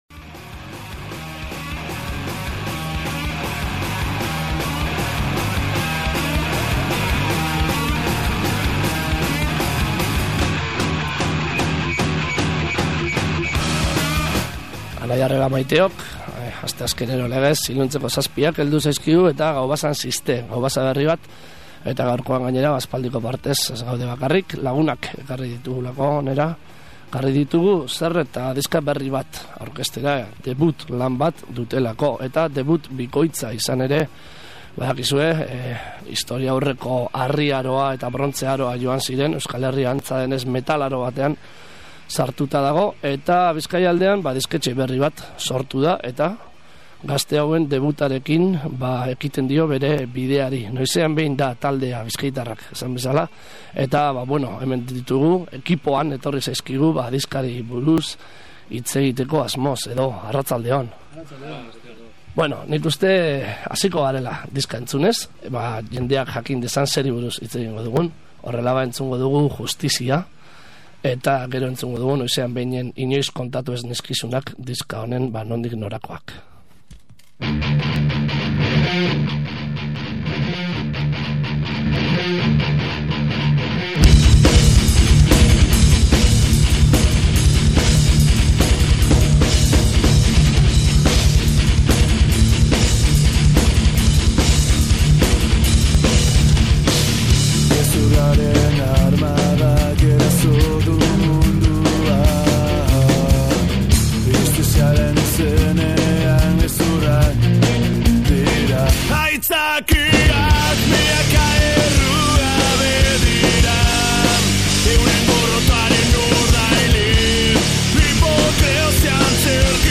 Aste honetako Gaubasan Gatikatik eta Mungiatik etorritako gonbidatuak izan ditugu. Hala da, metal doinuak jorratzen dituen Noizean behin taldearen partaideak izan dira gurean euren estraineko diskoa aurkezten.